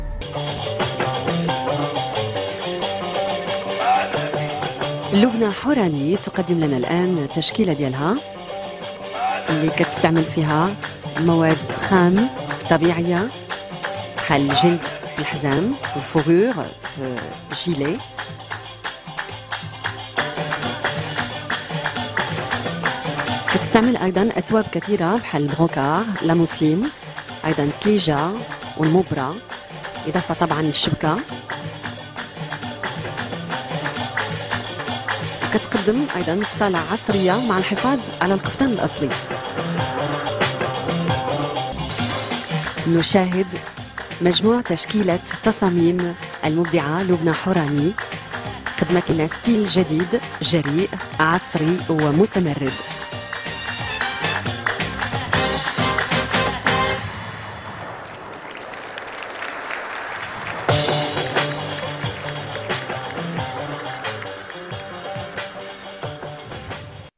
Narration Darija